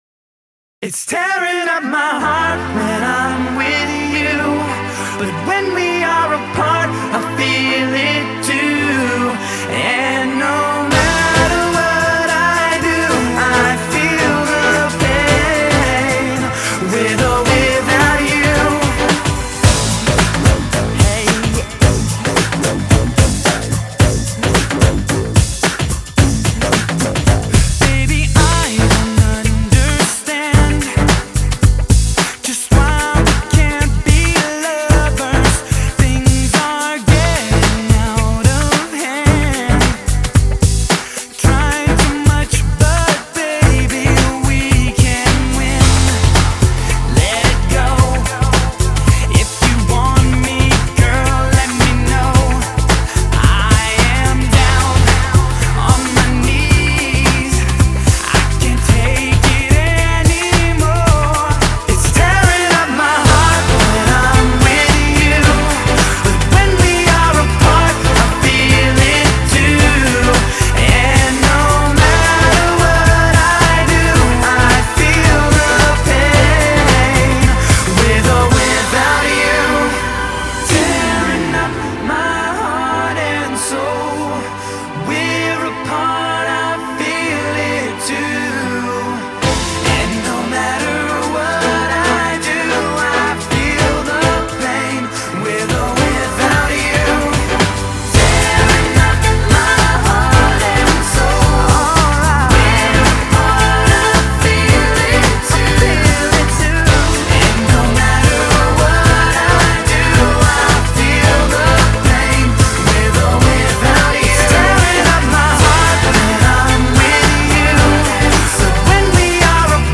BPM110
MP3 QualityMusic Cut